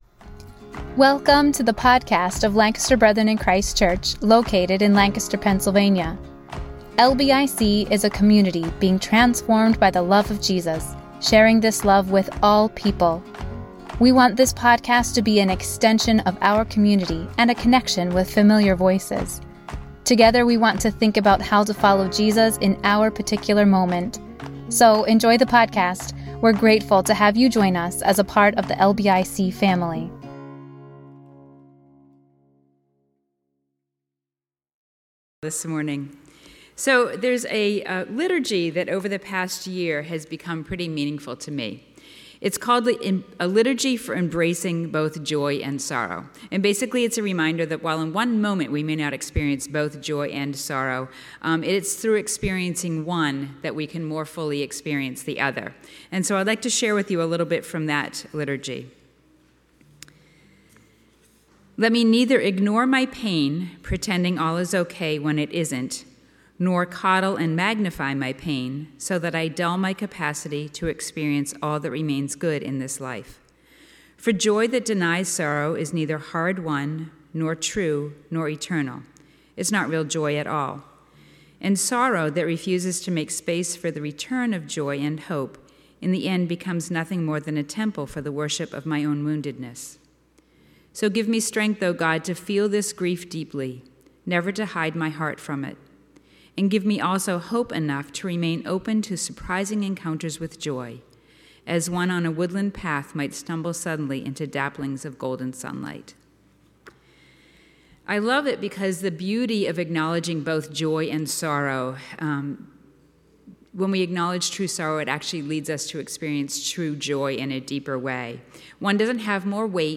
A message from the series "Eastertide."